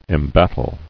[em·bat·tle]